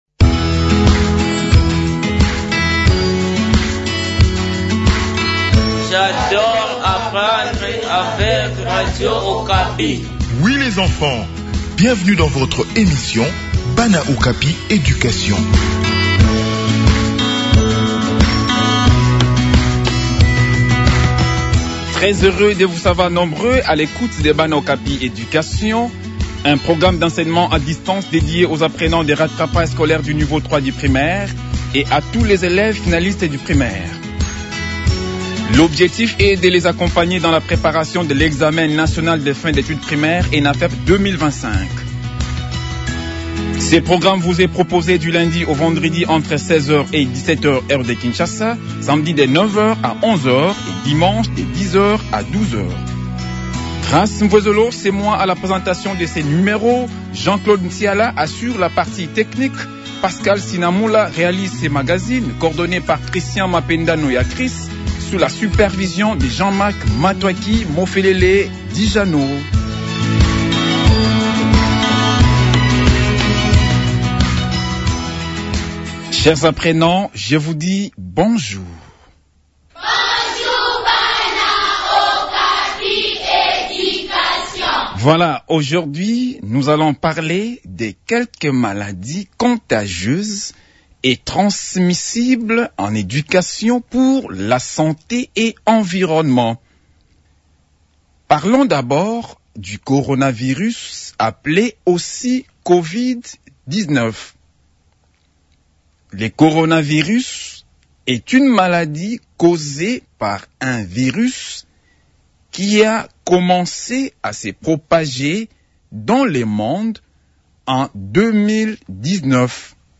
Préparations des examens nationaux : leçon sur les maladies transmissibles